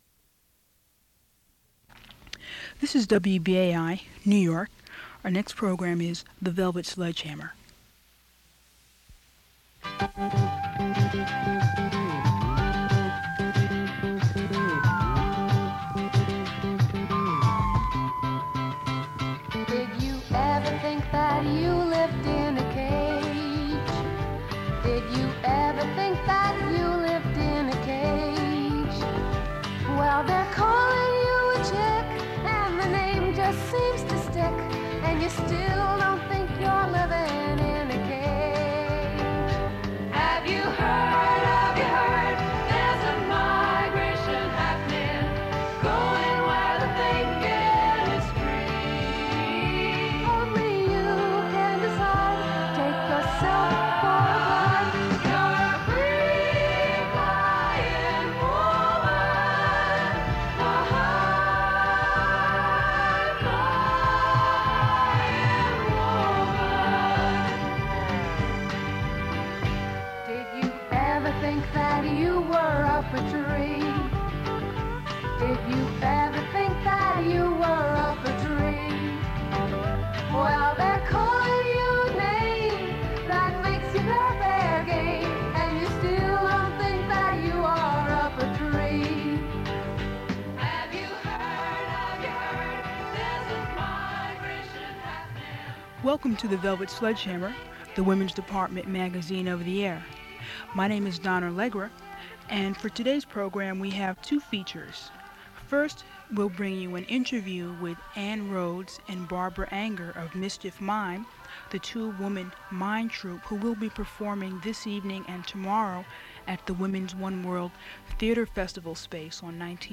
Radio talk shows